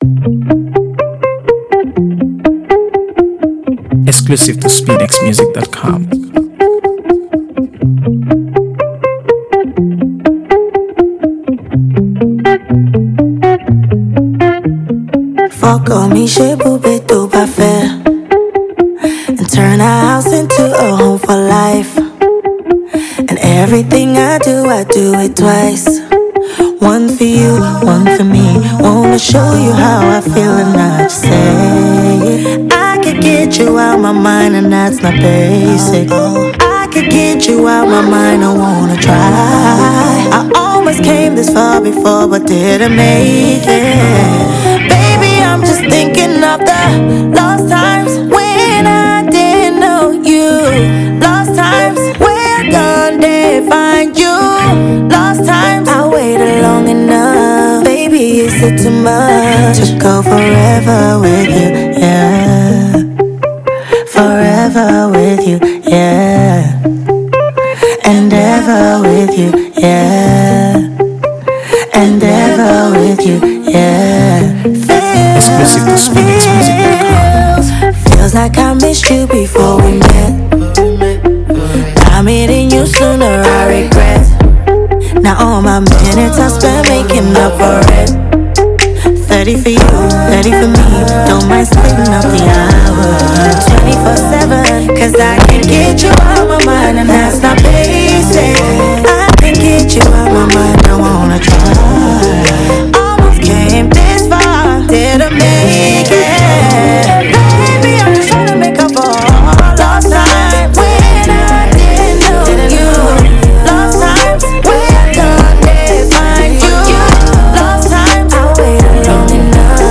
AfroBeats | AfroBeats songs
promising a fusion of diverse sounds and styles.